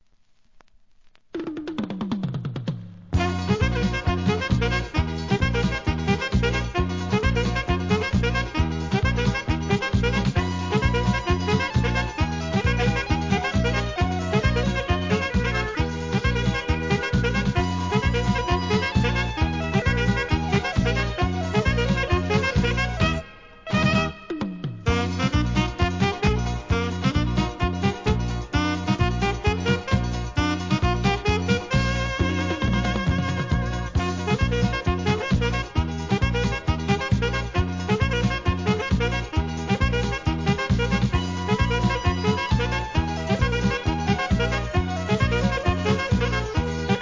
店舗 数量 カートに入れる お気に入りに追加 AFRO, BLAZILコンピレーション!!